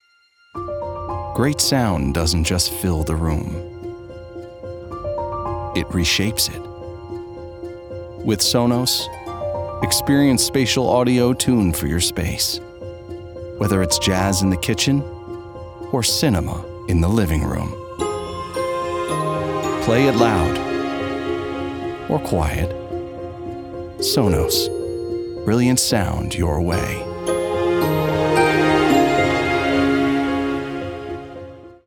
Smooth · Warm · Conversational
A warm, modern read for premium consumer brands. Authentic tone with understated confidence.